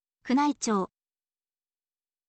kunaichou